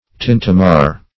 Search Result for " tintamar" : The Collaborative International Dictionary of English v.0.48: Tintamar \Tin`ta*mar"\, n. [F. tintamarre.]